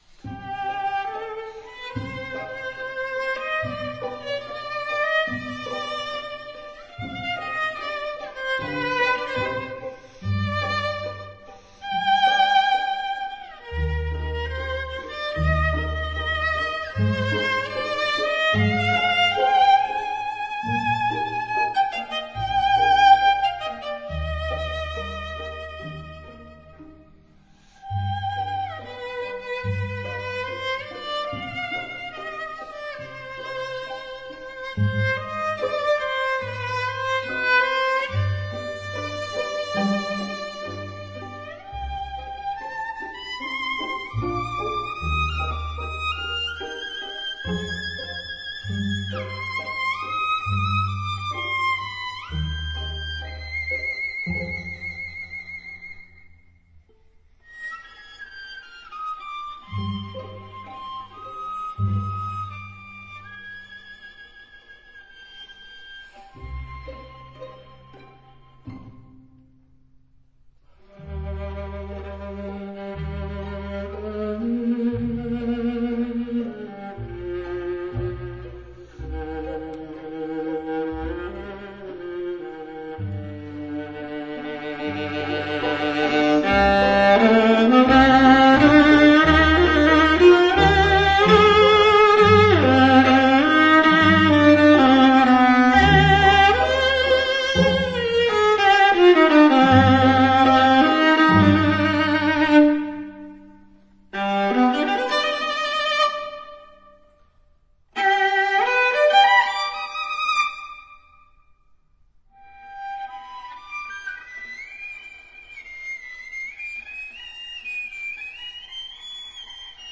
這張中提琴的唱片，被樂友推薦為「有史以來最正確的錄音」。
毫無疑問，這是演奏與錄音都非常好的成果。